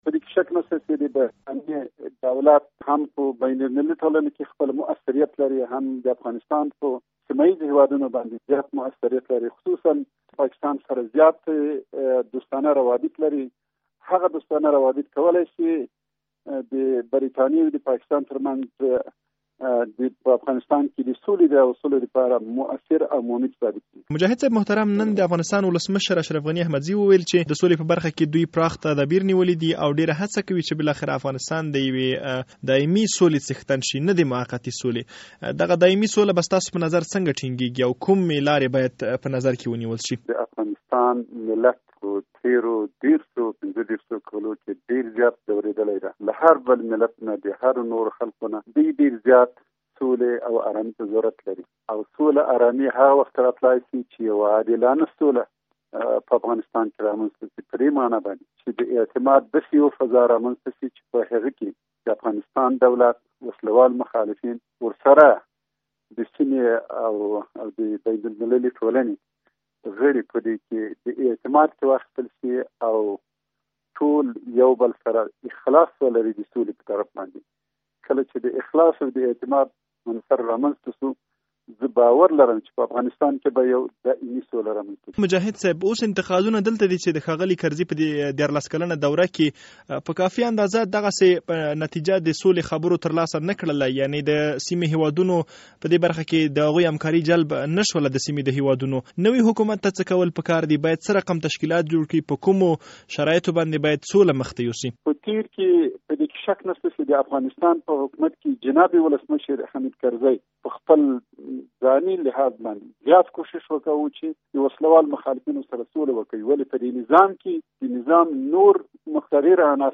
له عبدالحکیم مجاهد سره مرکه